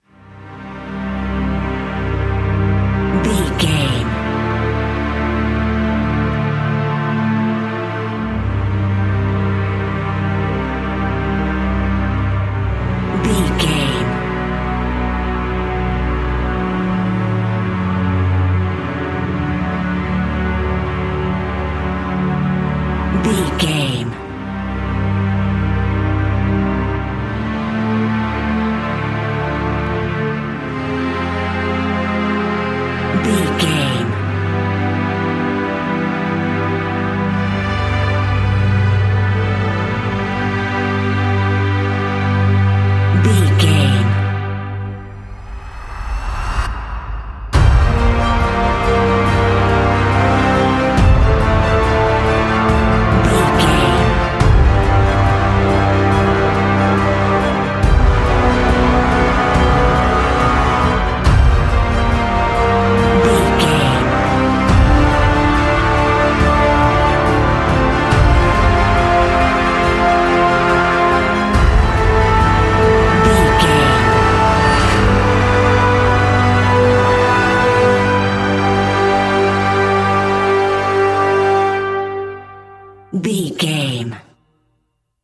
Ionian/Major